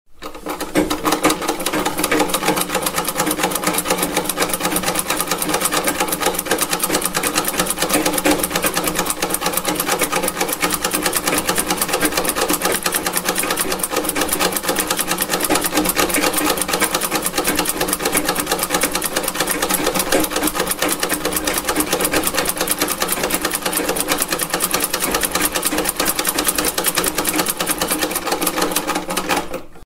В подборке – разные типы стежков, гула мотора и других характерных звуков.
Звук старой механической ножной швейной машинки